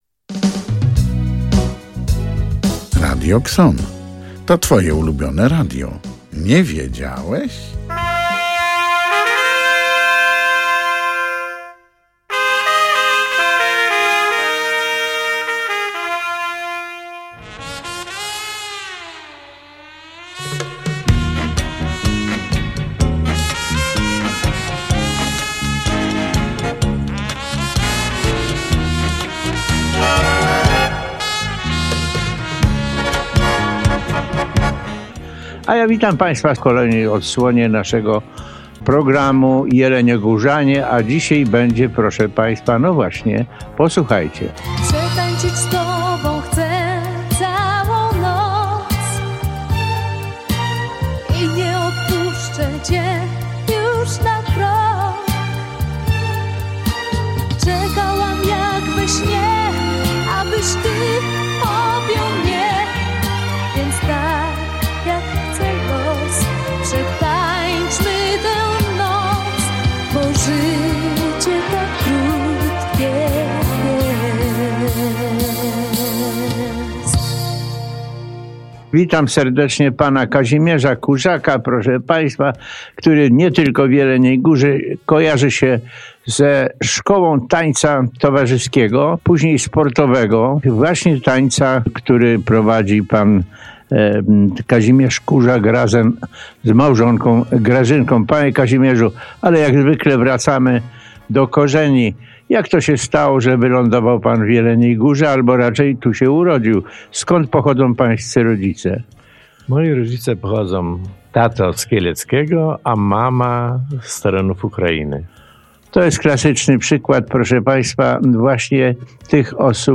Oto rozmowa